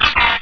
Cri de Cacnea dans Pokémon Rubis et Saphir.